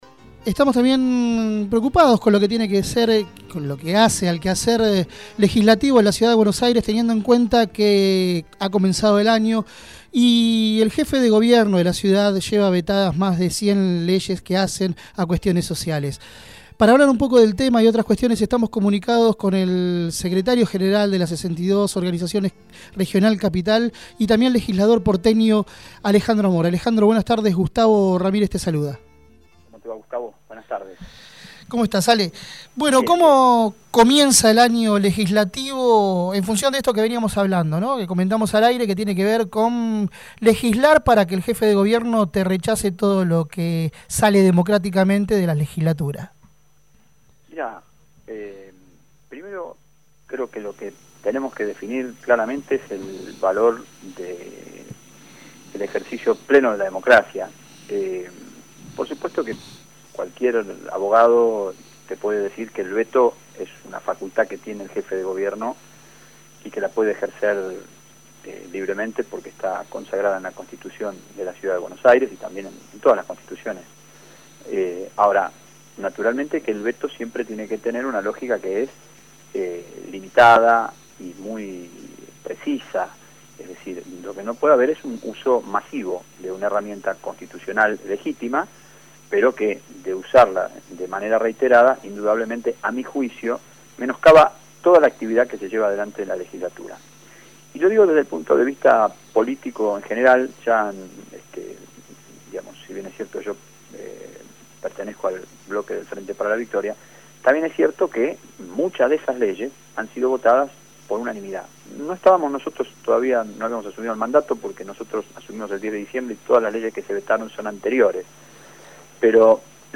Alejandro Amor, Secretario Gral. de las 62 Organizaciones Peronistas Regional Capital y Legislador porteño por el FPV, habló en Voces Portuarias.